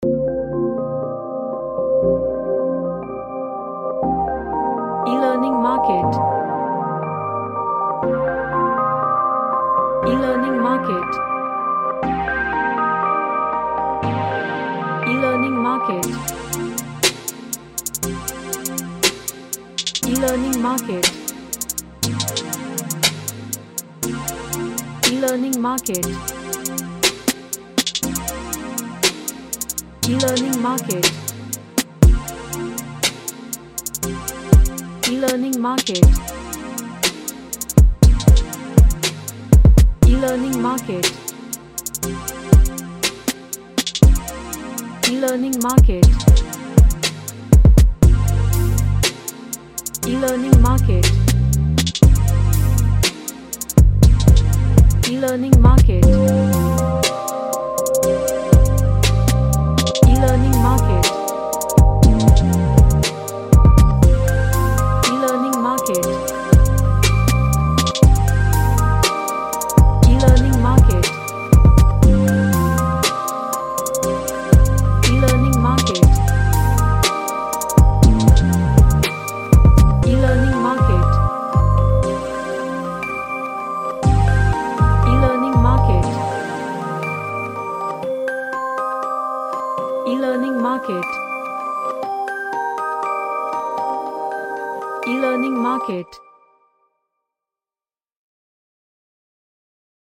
A chill type hip Hop track
Chill Out